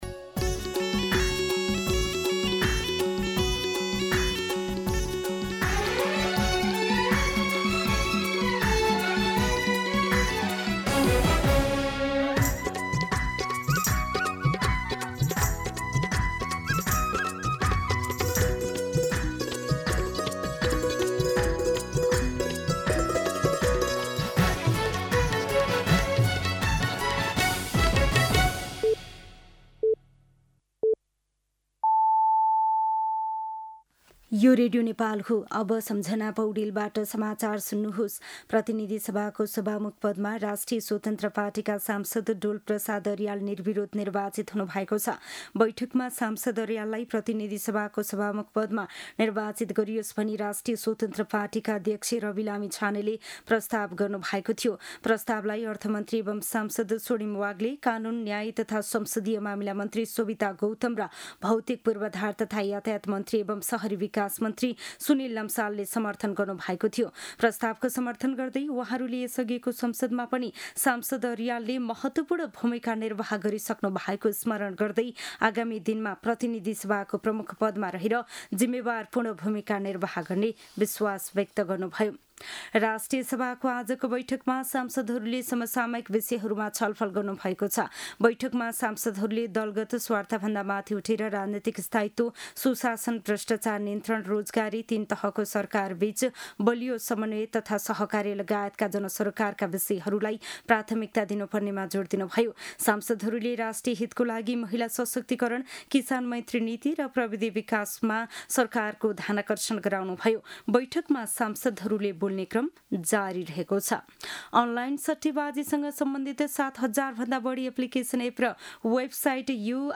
दिउँसो १ बजेको नेपाली समाचार : २२ चैत , २०८२